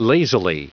Prononciation du mot lazily en anglais (fichier audio)
Prononciation du mot : lazily